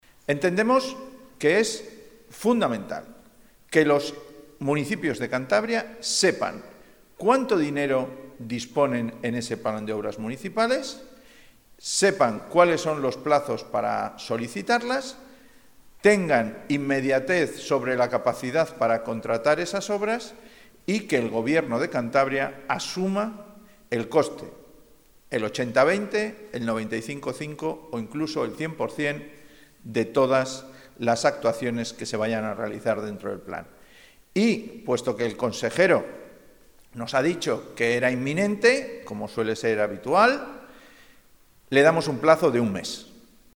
Ver declaraciones de Pedro Hernando, portavoz regionalista.
Pedro Hernando en la rueda de prensa que ha ofrecido hoy